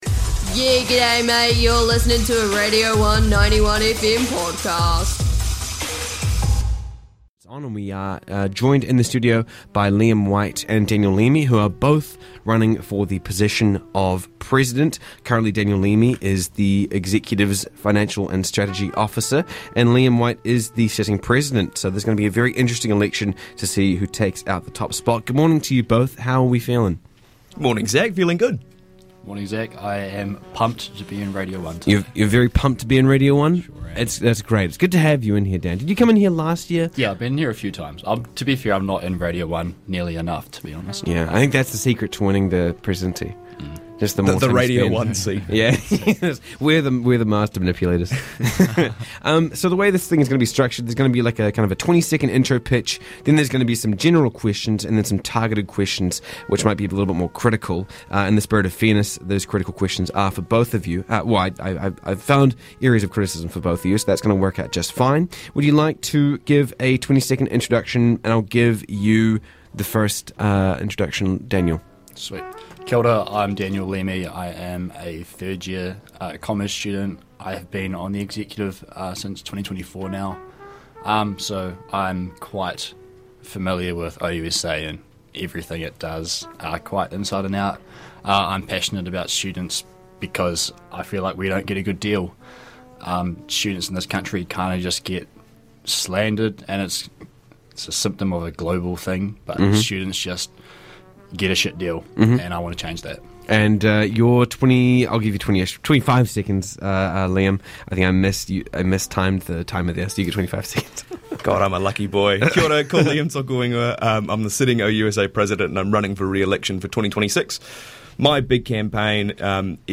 INTERVIEW: OUSA EXEC ELECTIONS - Presidential Candidates 2026